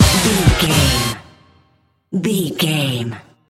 Ionian/Major
synthesiser
drum machine
Eurodance